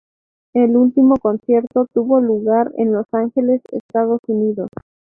con‧cier‧to
/konˈθjeɾto/